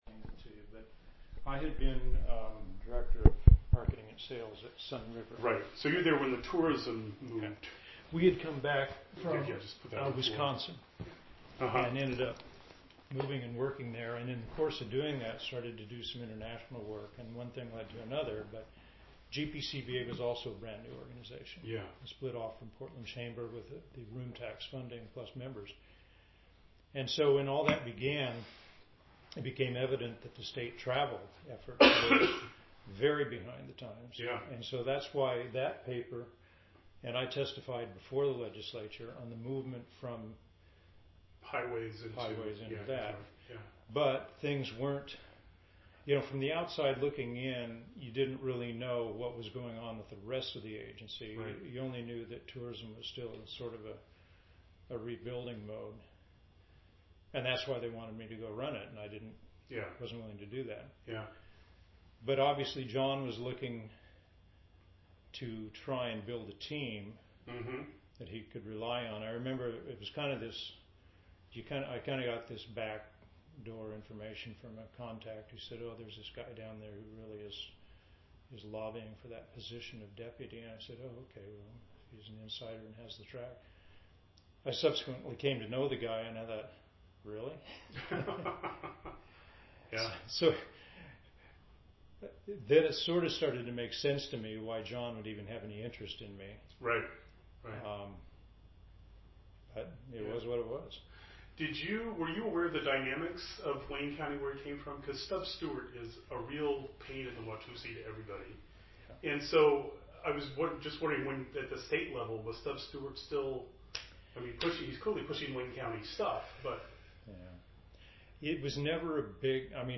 8430d886f98e071325d5e73cfccb7c1ee50ba268.mp3 Title Tom Kennedy interview on Atiyeh, Feb. 21, 2017 Description An interview of Tom Kennedy regarding Oregon's Governor Victor Atiyeh, recorded on Feb. 21, 2017. Kennedy served under Atiyeh as Director of Oregon's Economic Development Department.